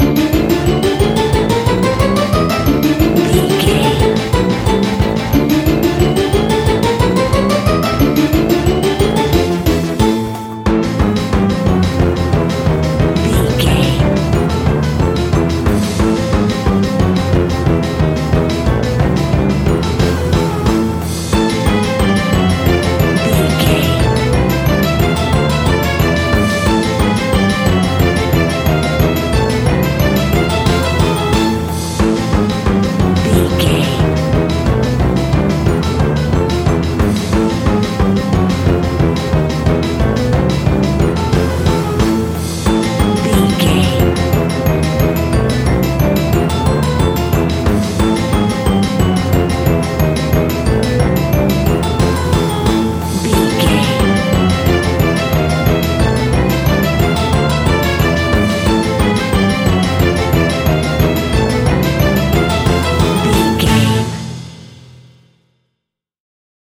Aeolian/Minor
scary
ominous
eerie
strings
brass
synthesiser
percussion
piano
spooky
horror music